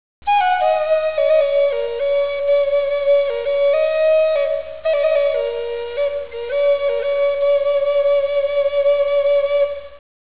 pipe.wav